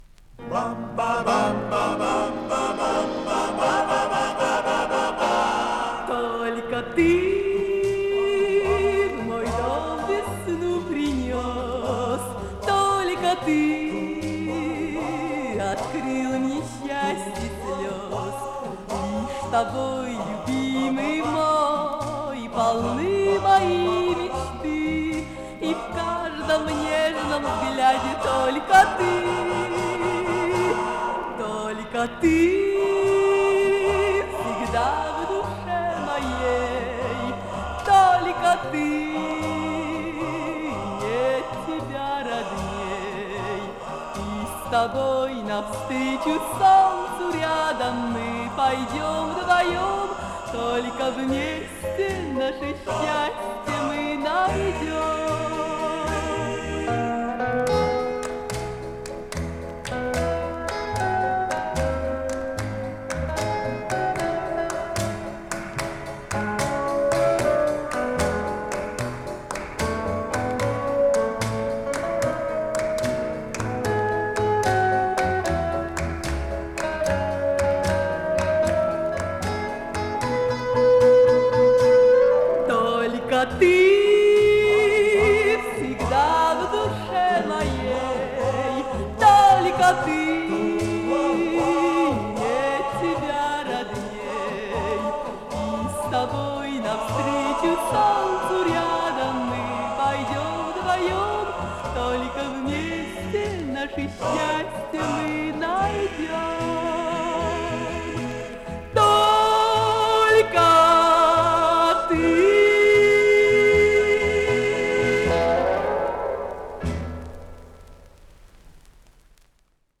1963 год, стерео